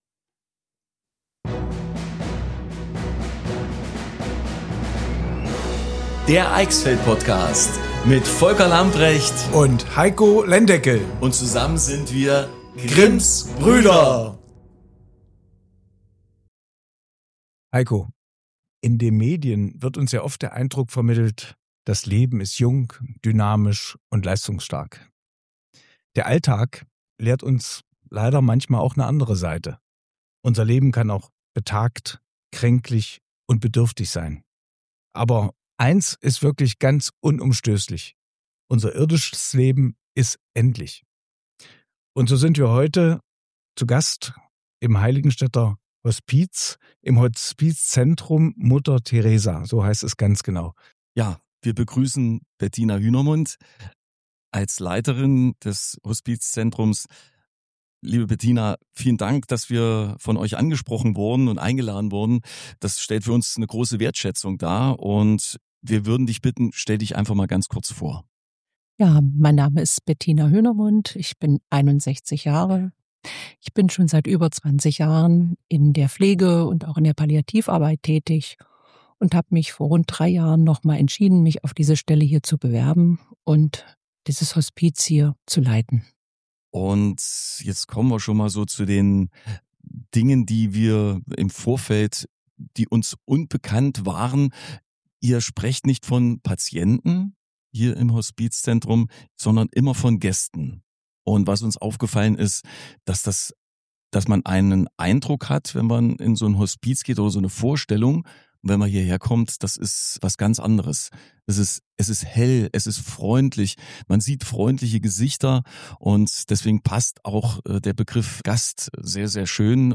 Beschreibung vor 3 Tagen Ein Gespräch über Würde, Architektur, Begleitung und Hoffnung Das Hospizzentrum Mutter Teresa in Heiligenstadt ist ein Ort, an dem Leben, Sterben, Hoffnung und Trauer in besonderer Weise zusammenfinden.